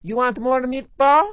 gutterball-3/Gutterball 3/Commentators/Maria/maria_moremeatball.wav at 608509ccbb5e37c140252d40dfd8be281a70f917